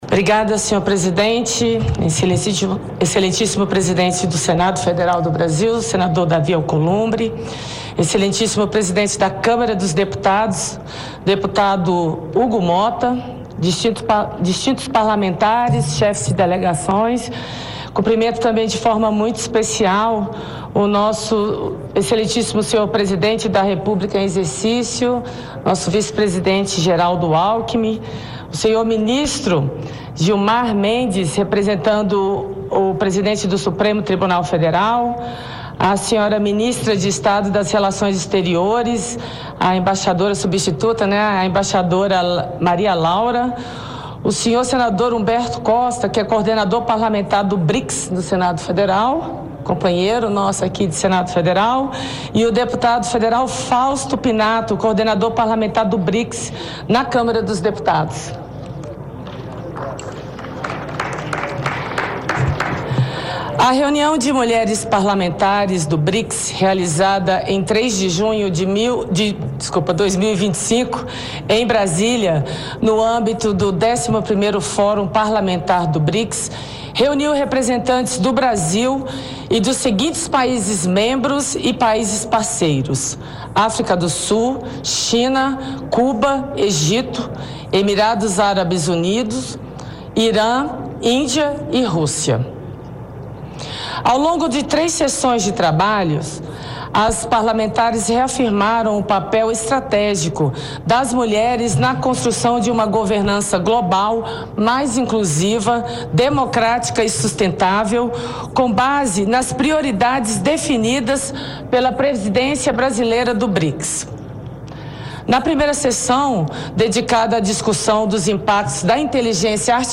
Ouça a íntegra do discurso da líder da Bancada Feminina, senadora Leila Barros, na abertura do 11° Fórum Parlamentar do Brics